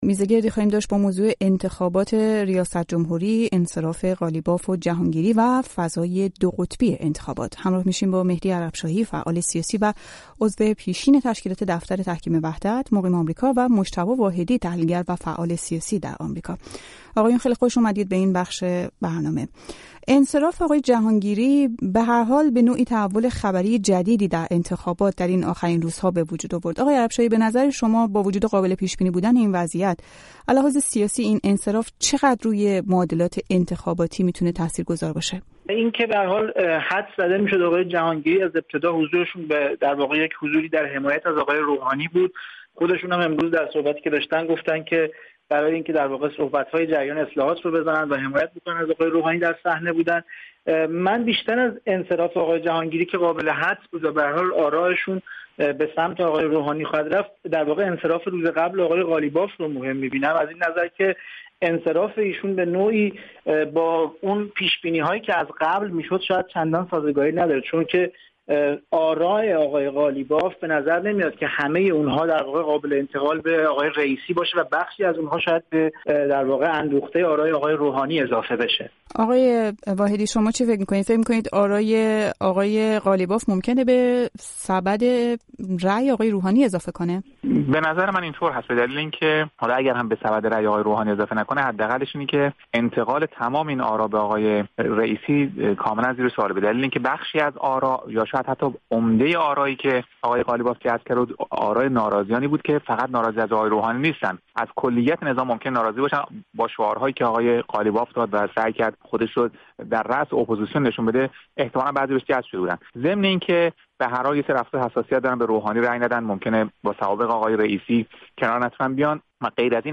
میزگردی داریم با موضوع انتخابات ریاست‌جمهوری، انصراف قالیباف و جهانگیری و فضای دو قطبی انتخابات.